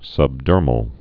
(sŭbdûrməl)